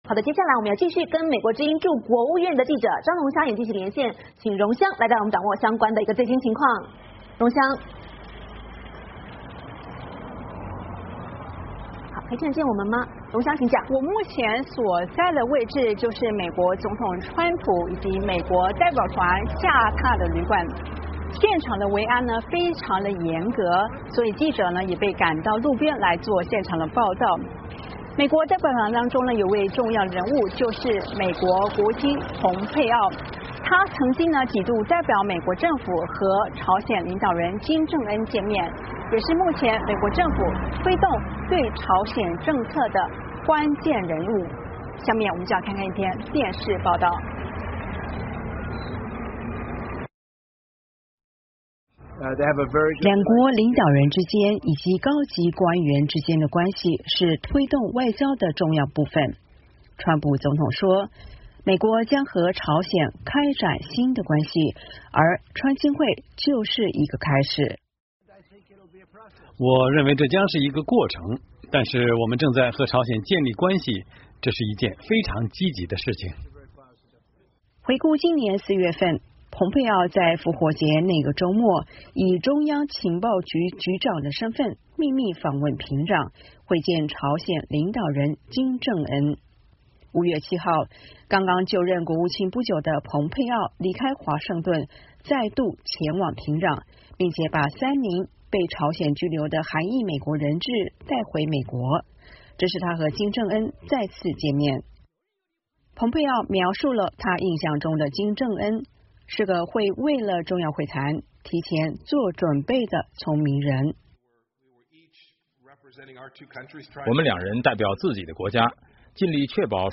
VOA连线